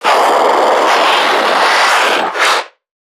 NPC_Creatures_Vocalisations_Infected [6].wav